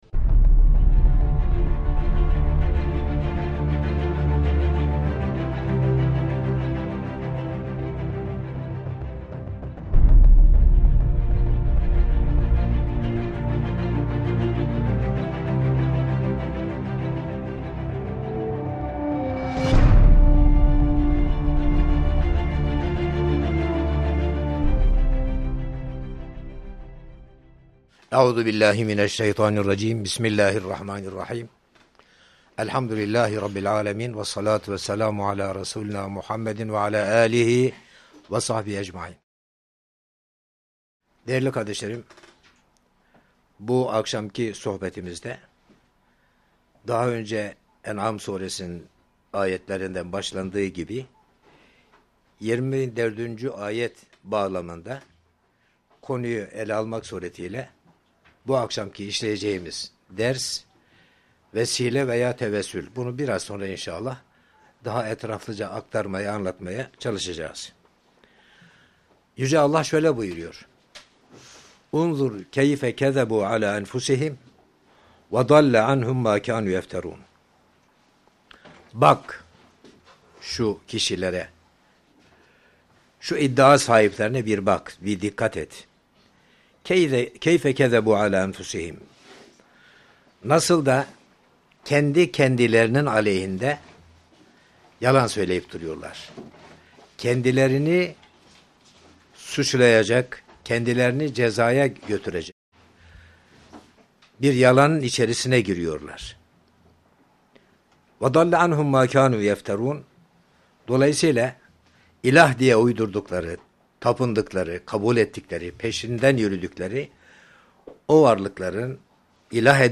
KUR’AN SOHBETLERİ | CAİZ OLAN VE OLMAYAN VESİLELER